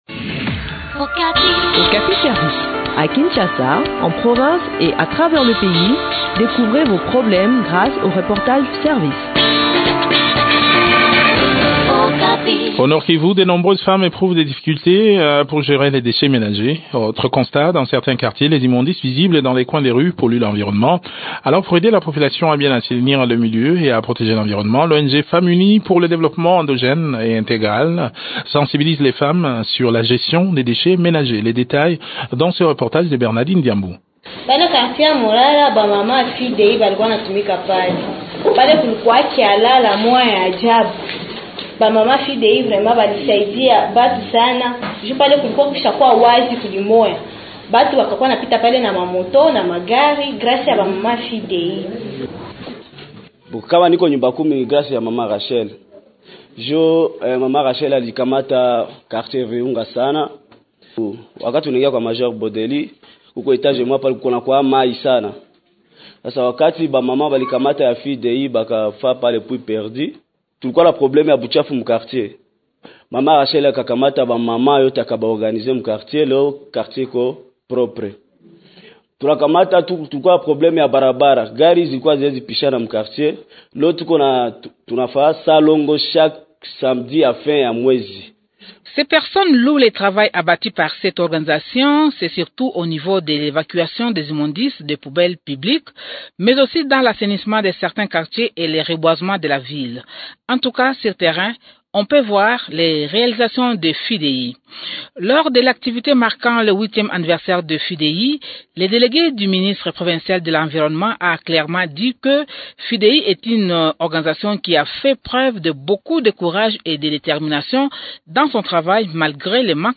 Le point sur le déroulement de cette activité dans cet entretien